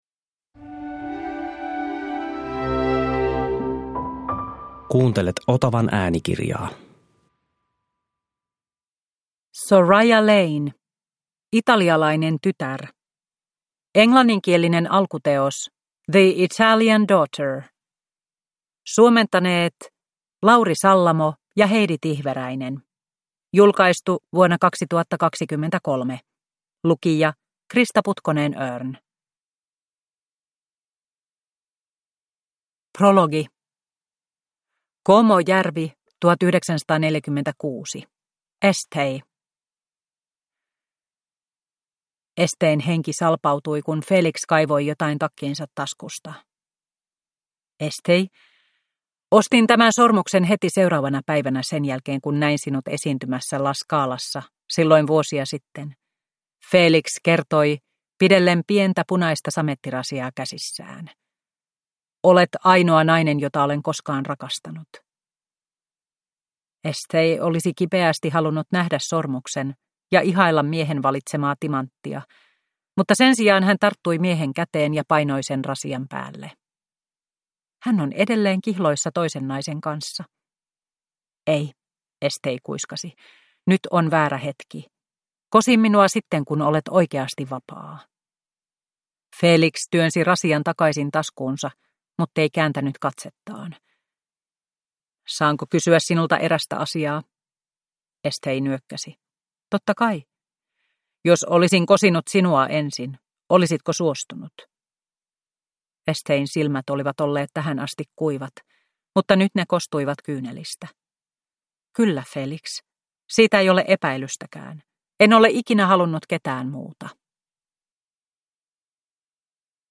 Italialainen tytär – Ljudbok – Laddas ner